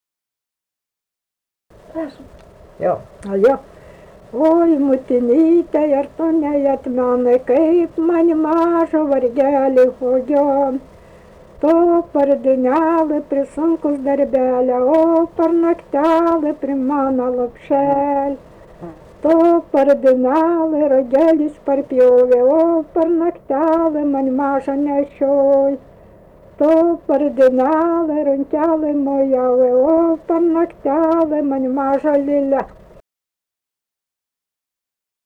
daina, vestuvių
Dičiūnai
vokalinis